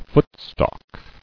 [foot·stalk]